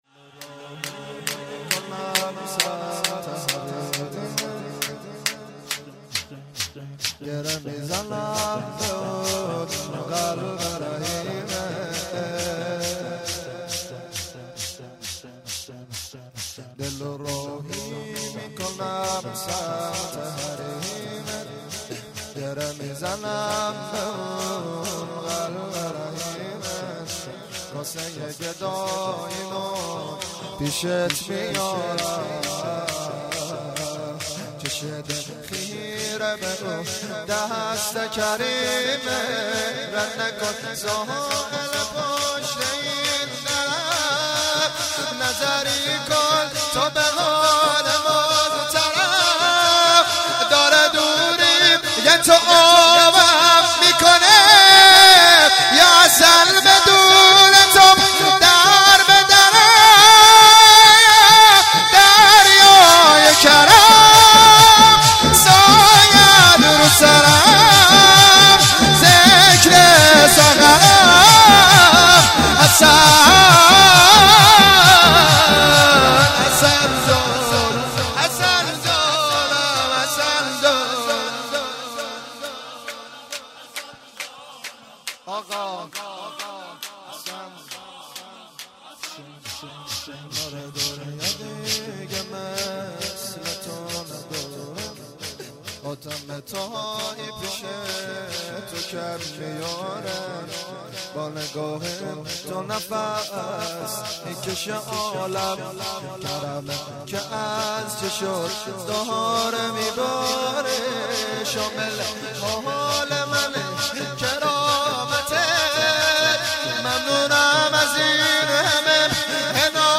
شور - دلُ راهی میکنم سمت حریمت
جشن ولادت حضرت زینب(س)- جمعه 29 دیماه